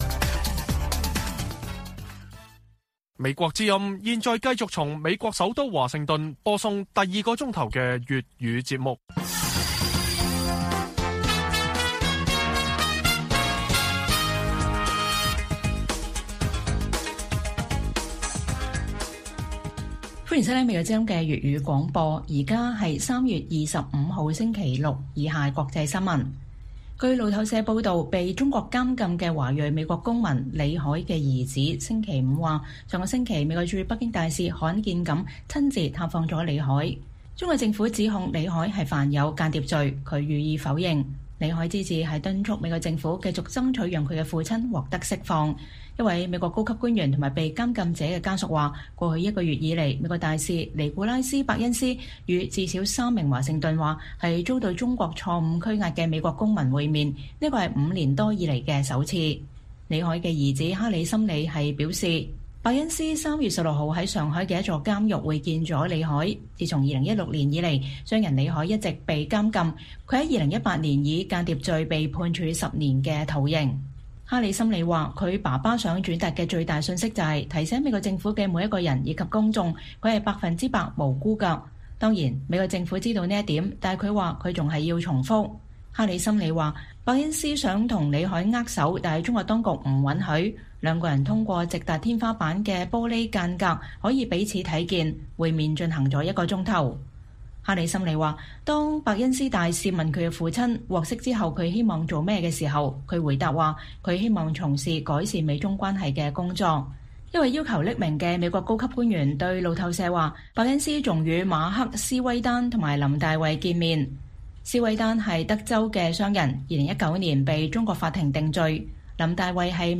粵語新聞 晚上10-11點：報導：伯恩斯大使探訪三名被中國監禁的美國公民